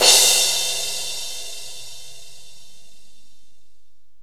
CYM18   01-L.wav